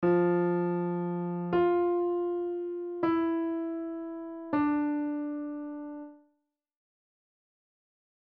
Here's another example, this time with F and F
then two different white notes